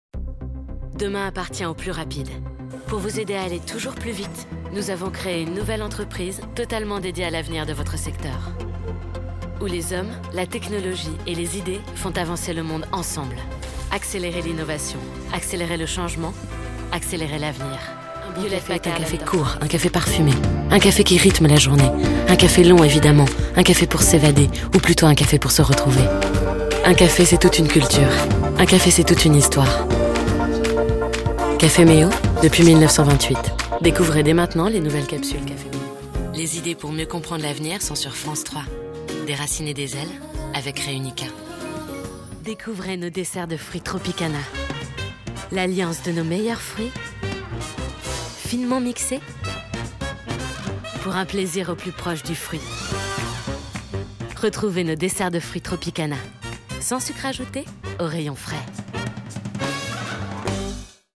Demo voix off